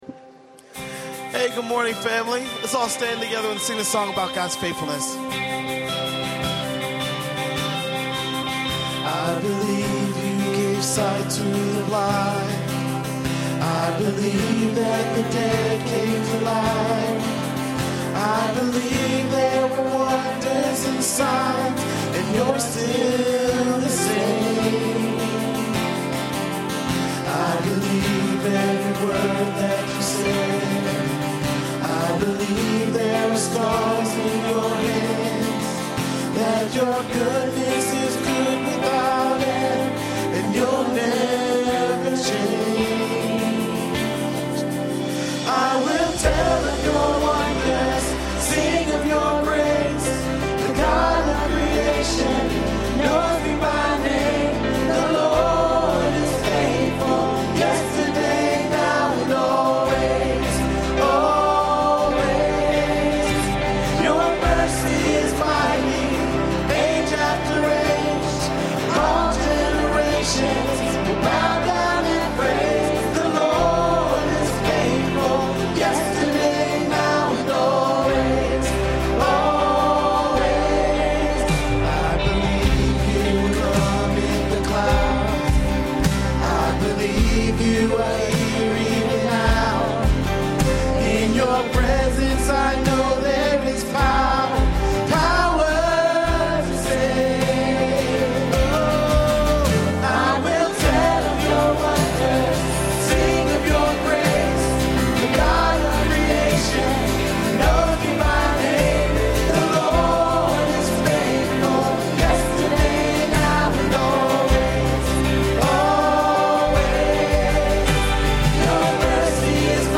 A message from the series "A Spirit-Filled Home."